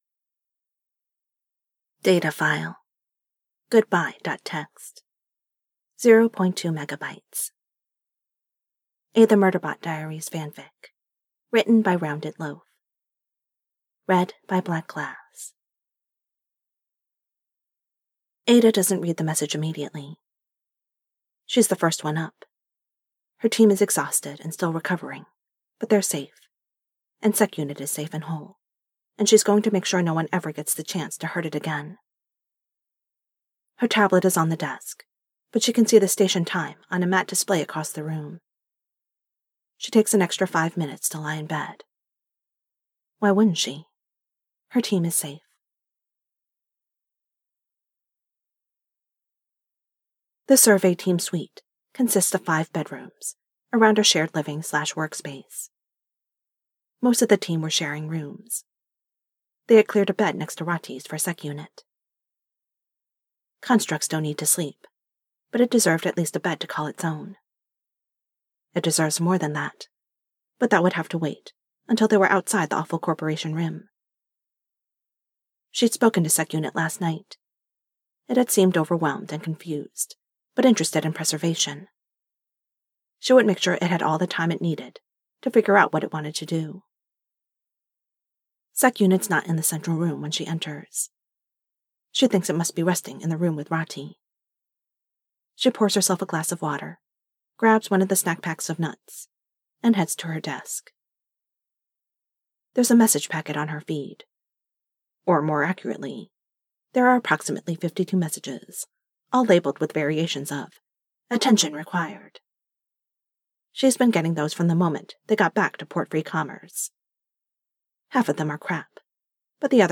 DATA FILE: goodbye.txt (0.2 MB) [Podfic]